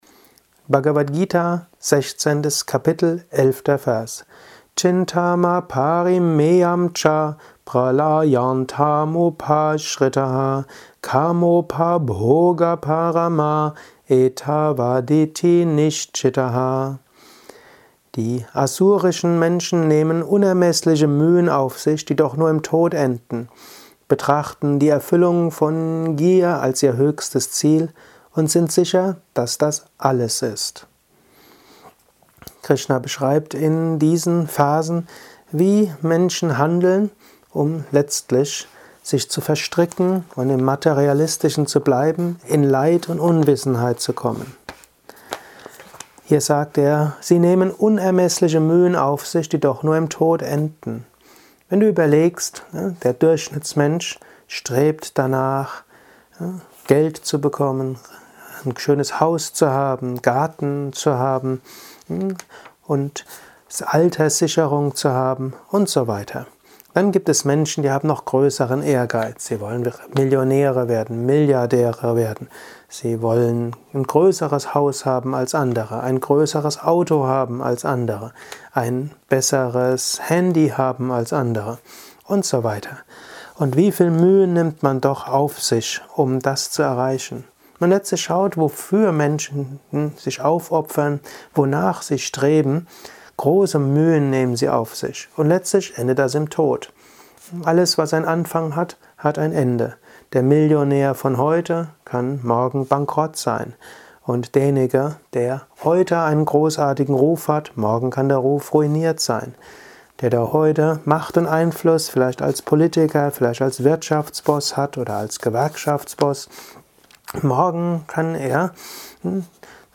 Dies ist ein kurzer Kommentar als Inspiration für den heutigen Tag